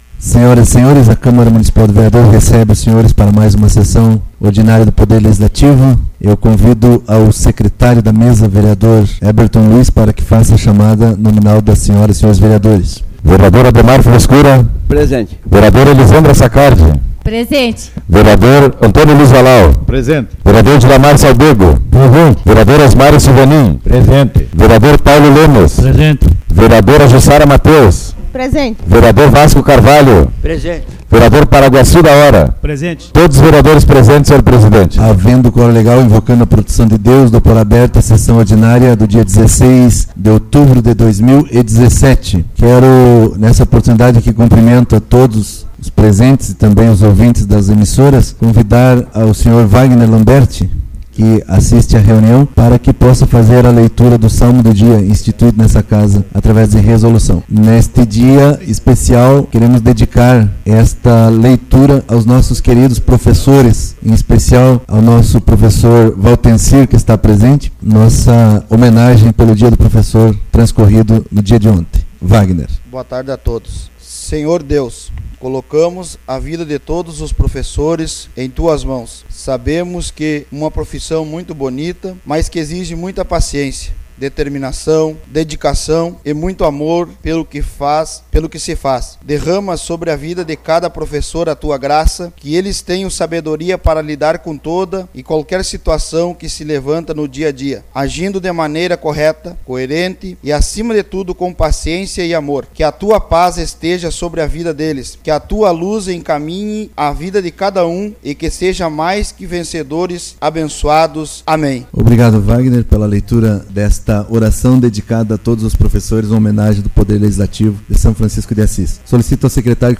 Reunião Ordinária.